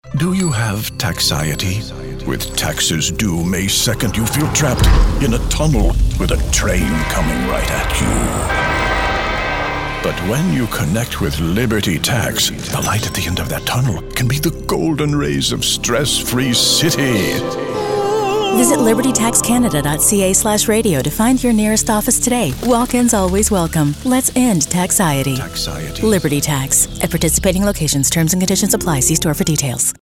Digital Home Studio
Shure KSM 32 Large diaphragm microphone
Radio Portfolio